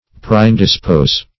Search Result for " preindispose" : The Collaborative International Dictionary of English v.0.48: Preindispose \Pre*in`dis*pose"\, v. t. To render indisposed beforehand.
preindispose.mp3